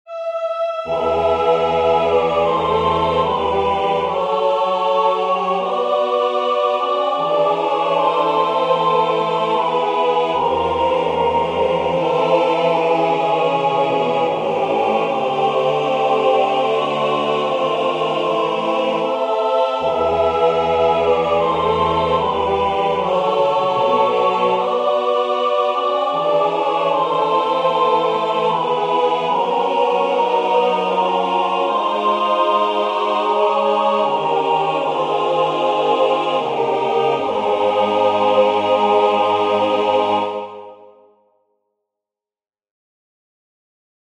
I don't think I've ever written a choral a capella piece like this before.